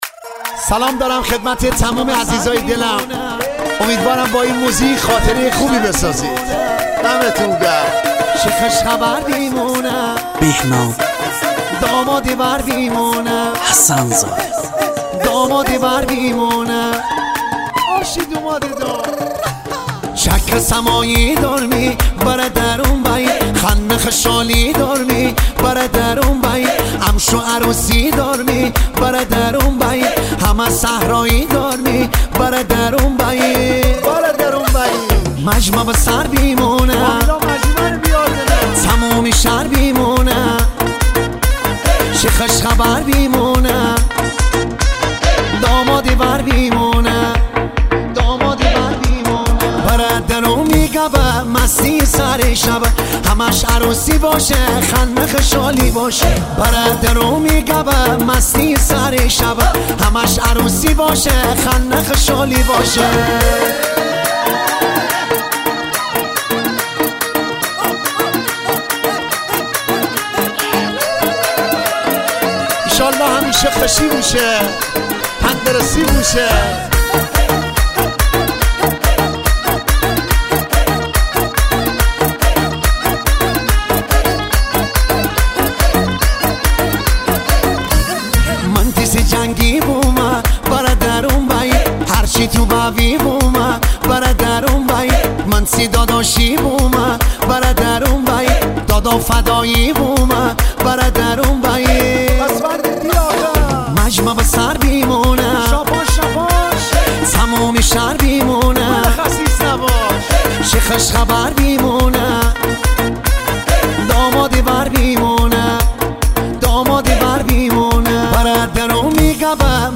با ترکیب منحصربه‌فرد ملودی‌های سنتی و ترکیب‌های مدرن